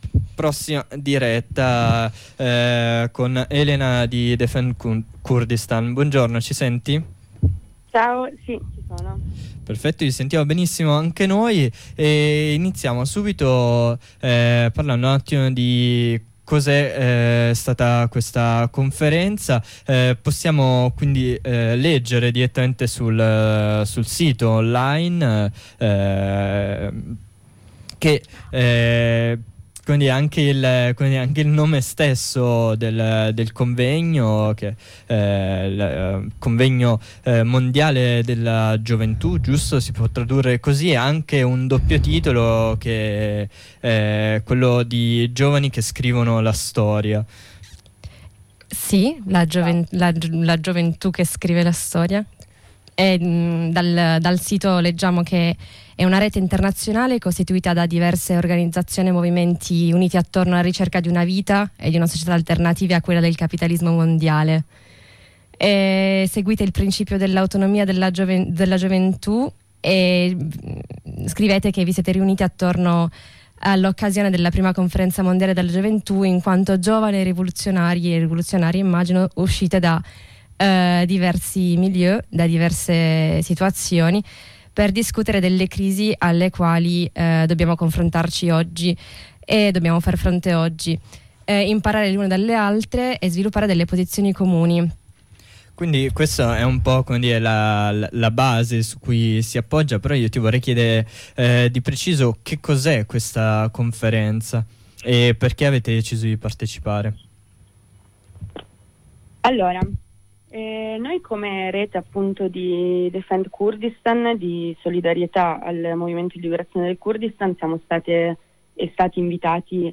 I temi centrali dell’incontro sono stati l’autonomia giovanile, il femminismo, la questione ecologica, il militarismo, l’educazione e la migrazione, tutti affrontati con uno sguardo giovanile. Di seguito l’intervista ad un’attivista di Defend Kurdistan che ha partecipato alla Conferenza: